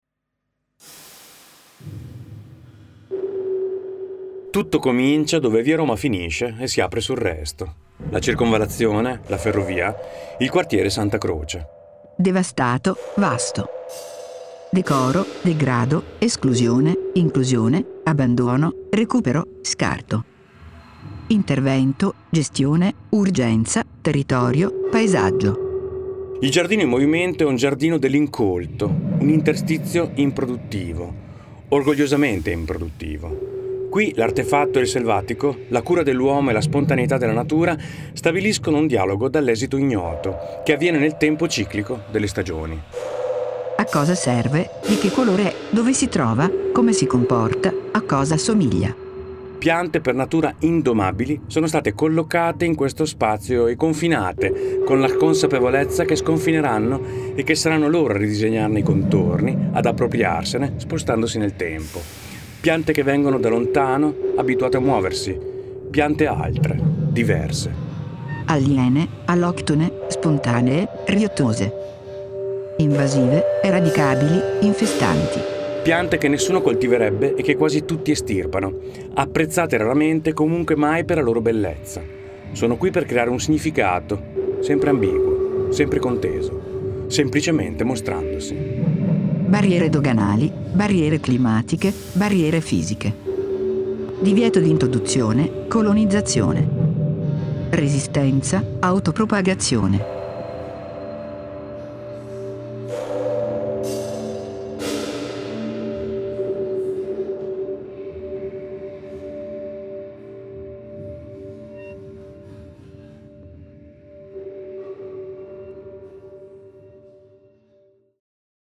letto da Max Collini